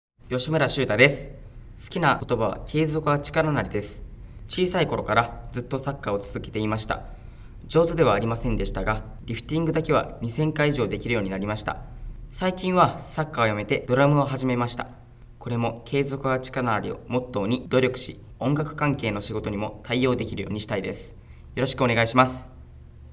出⾝地・⽅⾔ 兵庫県・関西弁
ボイスサンプル